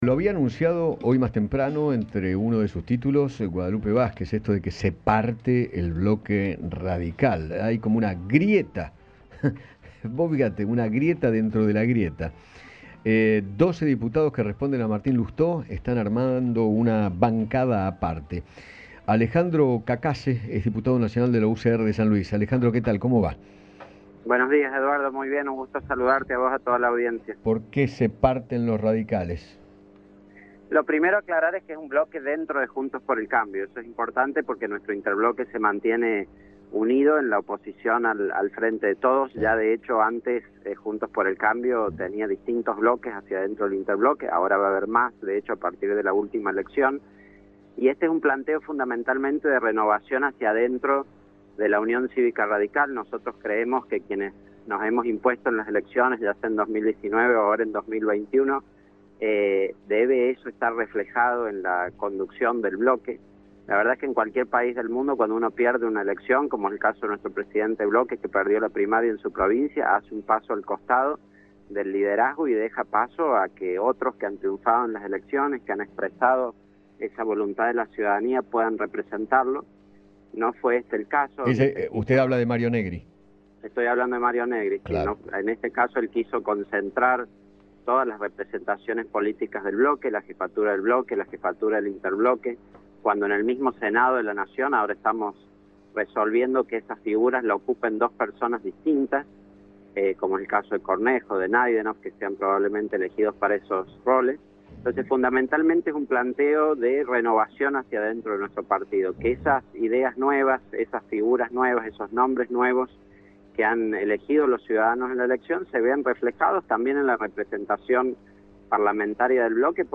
El diputado nacional Alejandro Cacace habló con Eduardo Feinmann sobre UCR Evolución, el nuevo bloque que dieron a conocer, el cual, responde a Martín Lousteau y a Emiliano Yacobitti, y propone al diputado Rodrigo de Loredo para presidirlo.